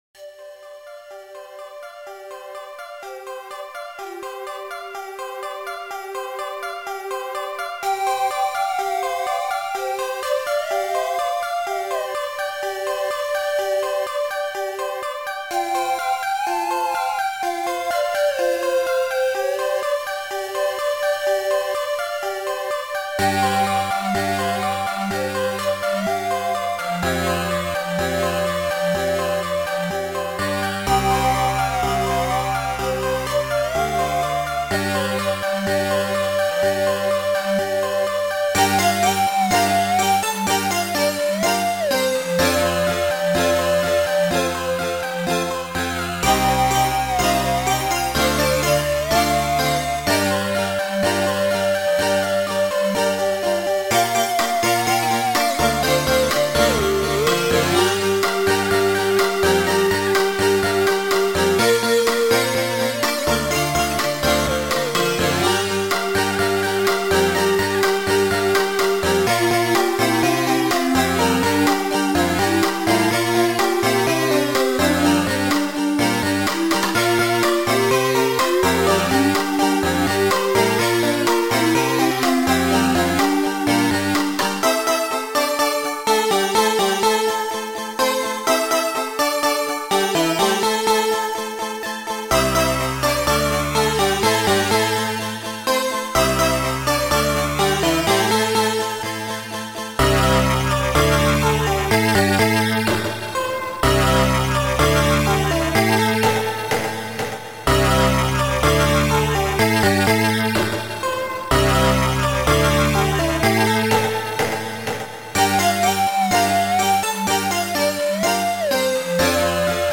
Sound Format: ChipTracker